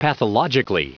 Prononciation du mot pathologically en anglais (fichier audio)
Prononciation du mot : pathologically